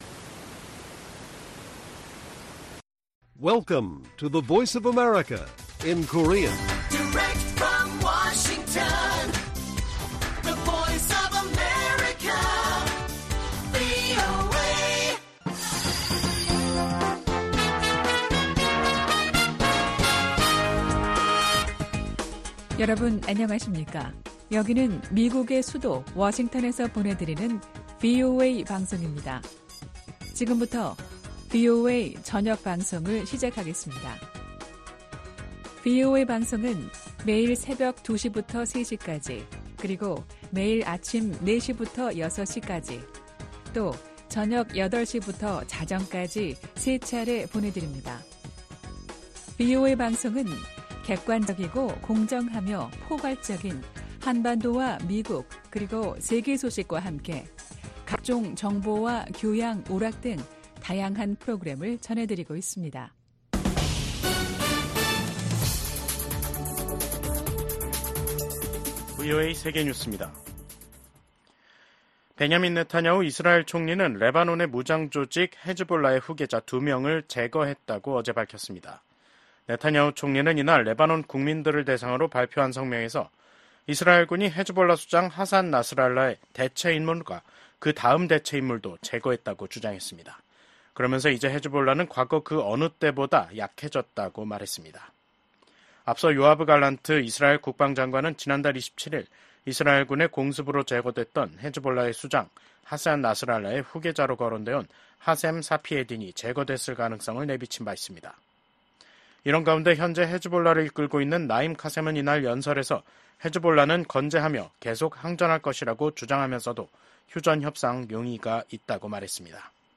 VOA 한국어 간판 뉴스 프로그램 '뉴스 투데이', 2024년 10월 9일 1부 방송입니다. 북한은 한국과 연결되는 도로와 철도를 끊고 한국과의 국경을 영구 차단하는 공사를 진행한다고 선언했습니다. 군축과 국제안보를 담당하는 유엔총회 제1위원회에서 북한의 대량살상무기 개발과 북러 군사협력에 대한 규탄이 이어지고 있습니다. 북한이 우크라이나 도네츠크 지역에 인력을 파견했다는 보도와 관련해 미 국방부는 북한의 대러 지원 움직임을 주시하고 있다고 강조했습니다.